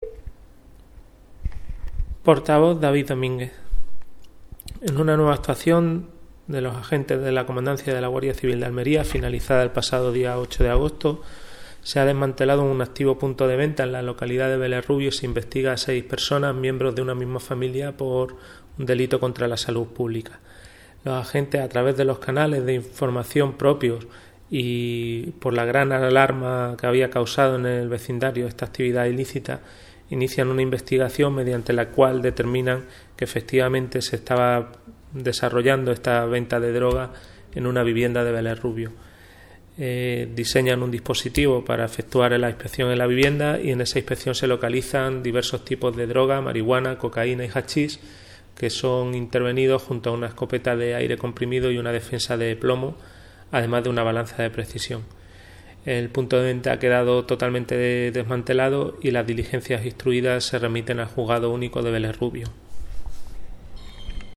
Declaraciones: